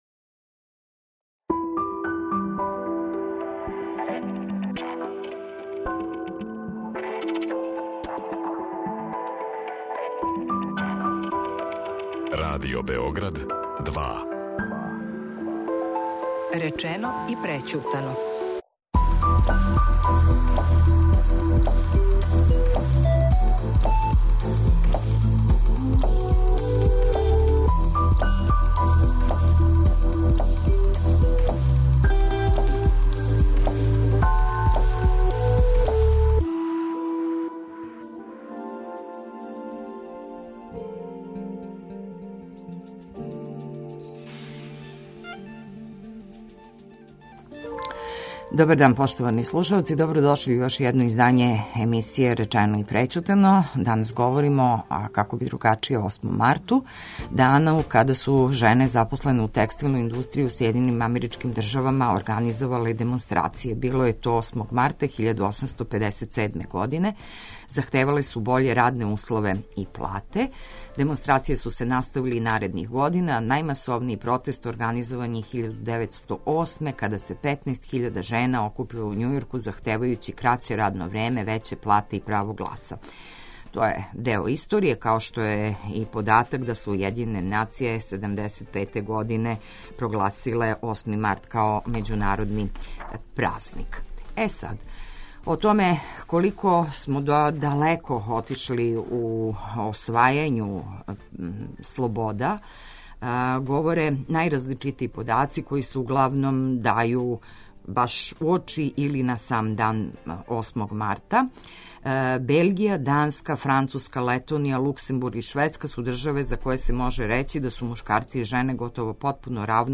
Гошће емисије